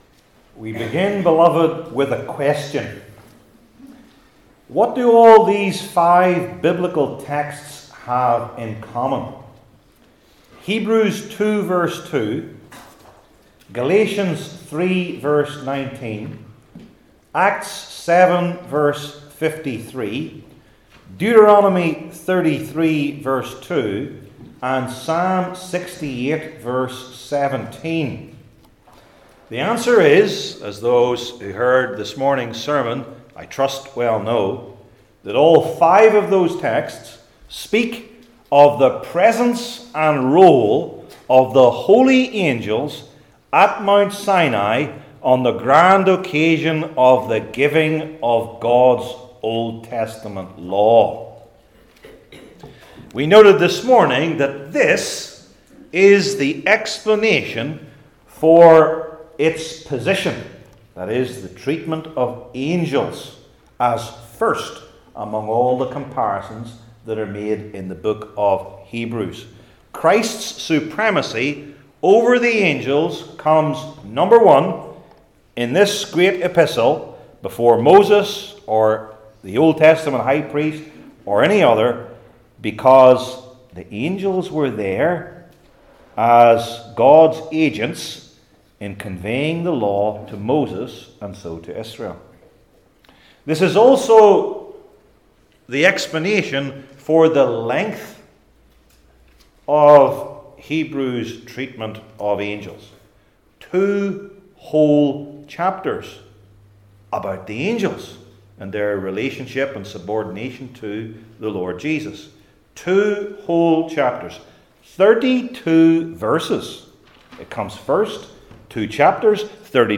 Hebrews 1:7 Service Type: New Testament Sermon Series I. What Is Their Role?